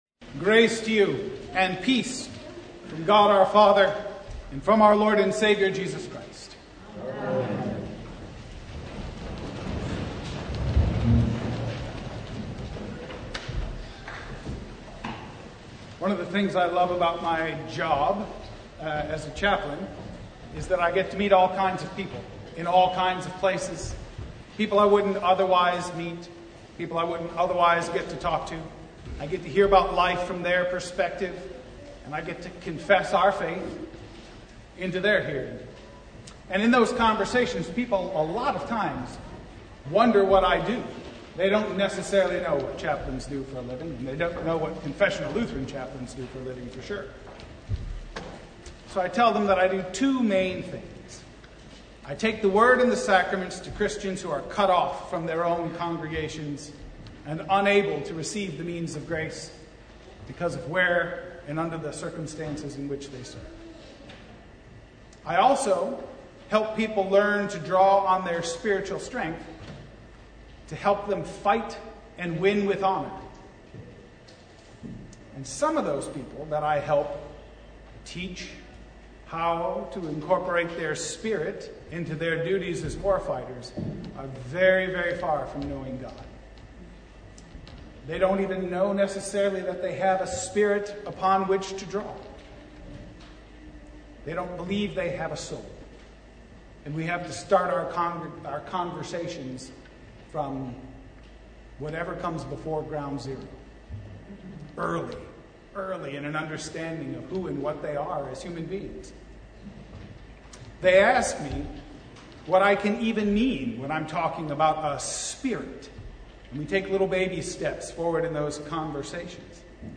Sermon from Second Sunday in Apostles’ Tide (2023)